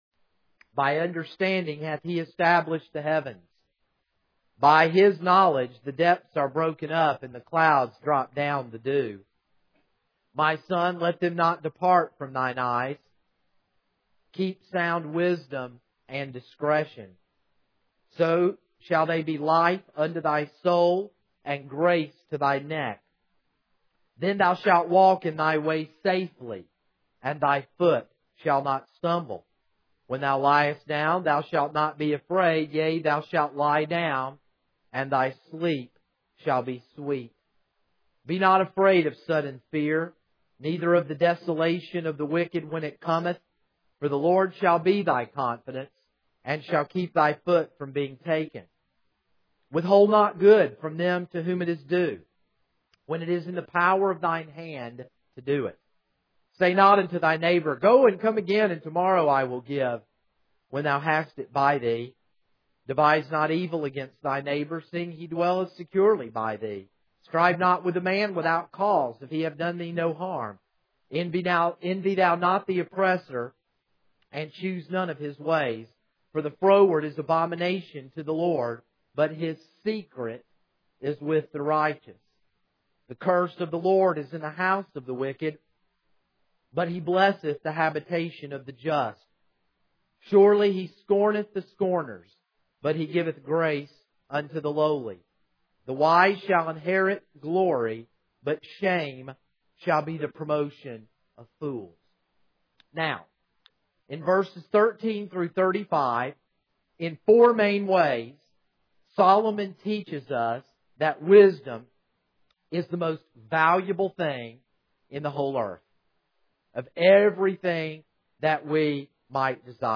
This is a sermon on Proverbs 3:13-35.